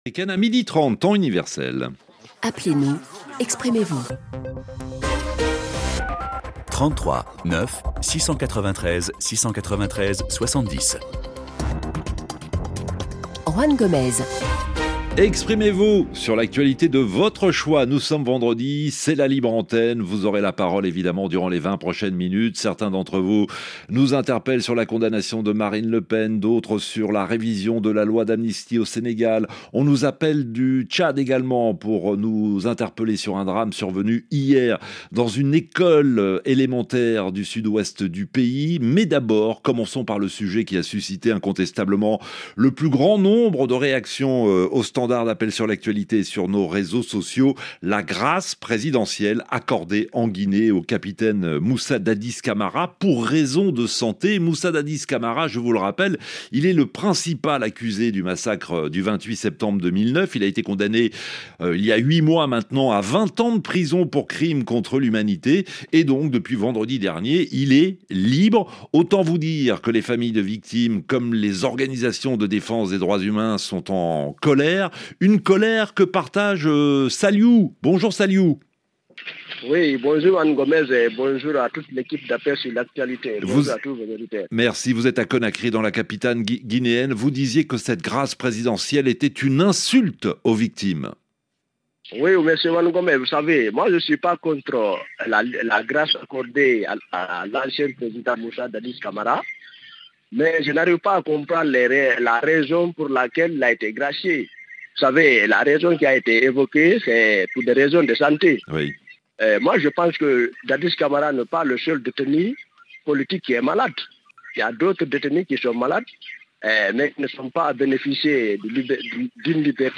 Le rendez-vous interactif des auditeurs de RFI.